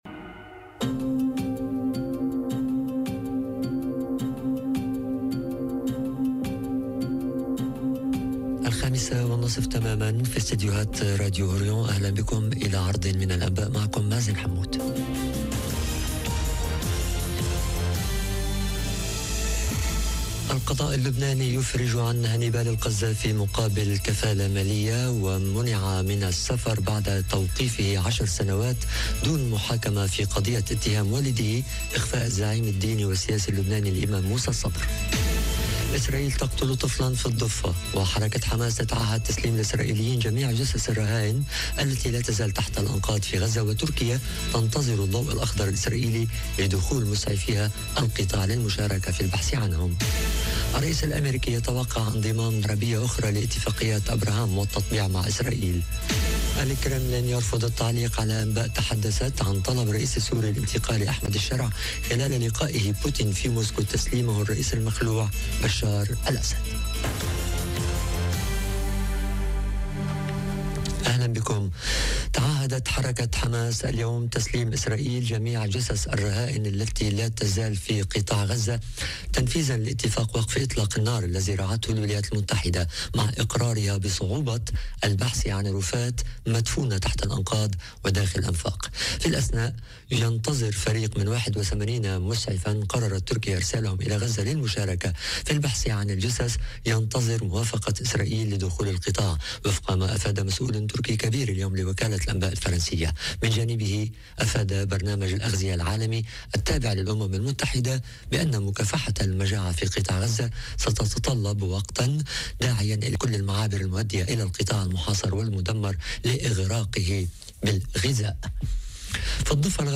نشرة الأخبار: القضاء اللبناني يفرج عن هانيبال القذافي، واسرائيل تقتل طفلاً في الضفة، وترامب يتوقع انضمام أطراف جديدة لاتفاقيات أبراهام - Radio ORIENT، إذاعة الشرق من باريس